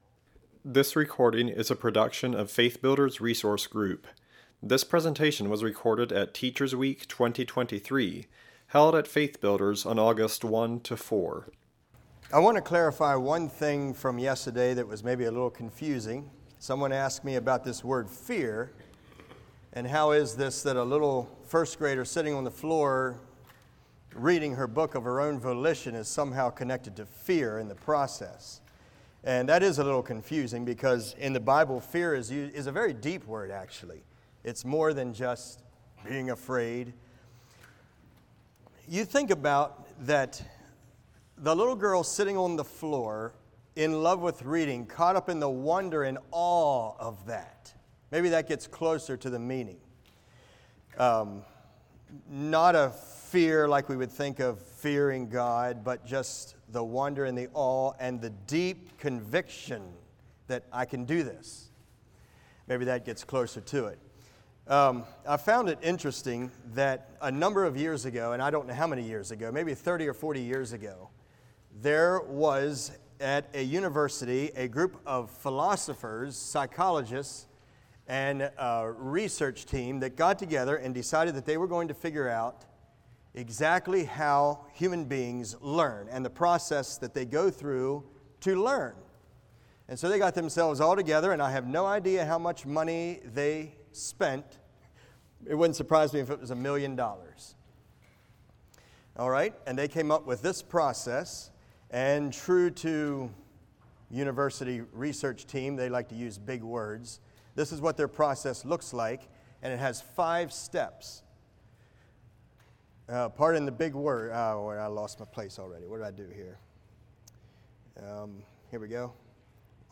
Home » Lectures » Creativity Wins a Listener